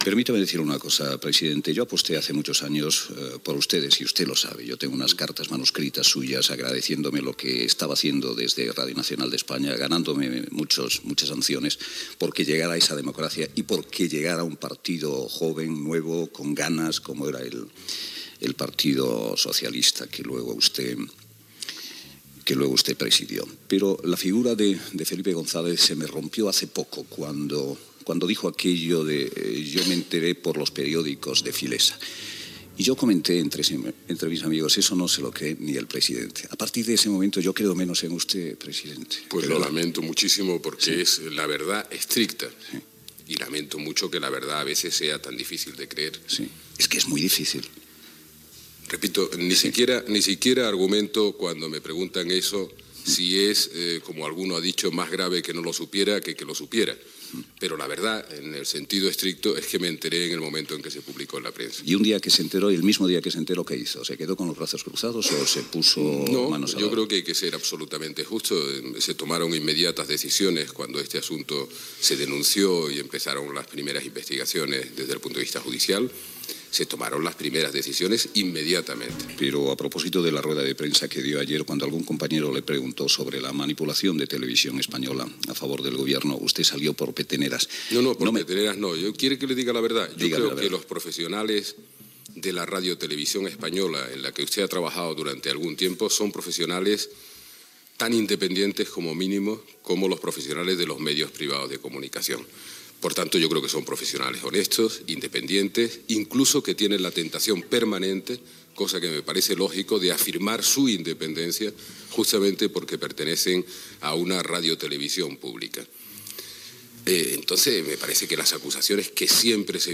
Fragment d'una entrevista al president del govern espanyol, Felipe González al Palau de la Moncloa.